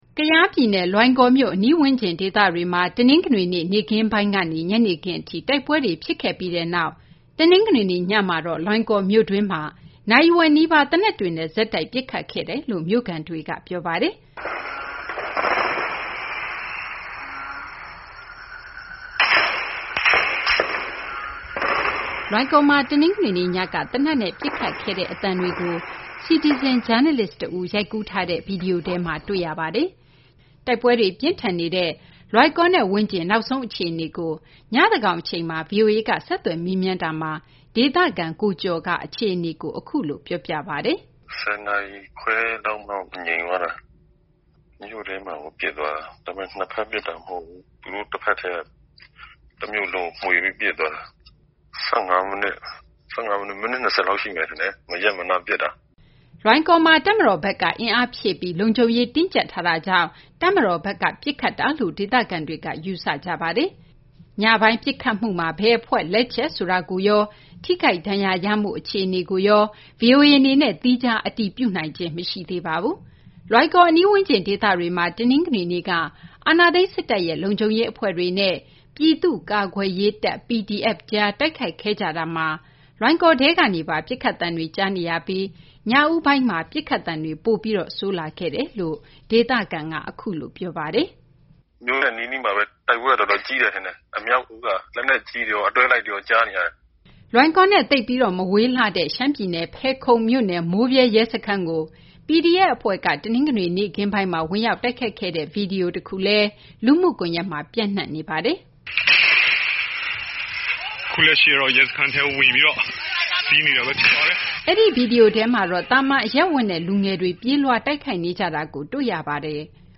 လွိုင်ကော်ပစ်ခတ်မှု ဒေသခံပြောပြချက်